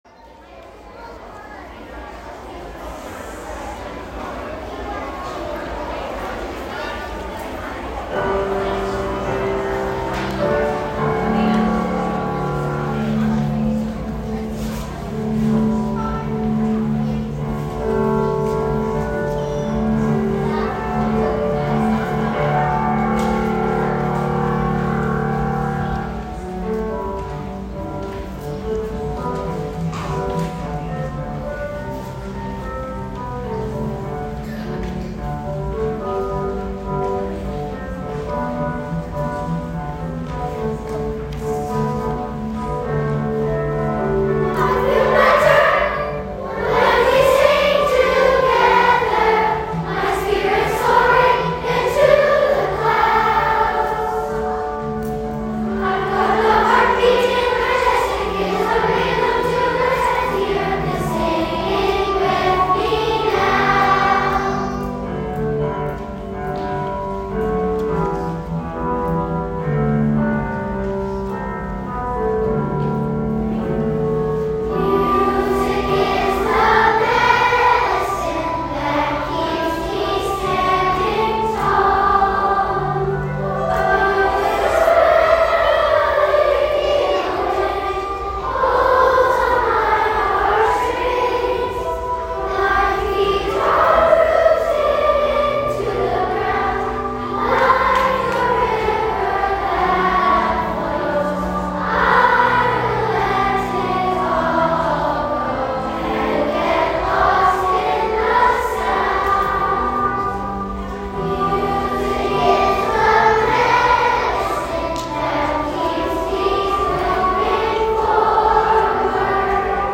Rousseau students and staff participated in their very first whole-school assembly since early 2020! Students were buzzing with excitement, as they came to share Music with fellow students through song!
Here is a sound recording of all of Rousseau performing “Music Is Our Medicine”!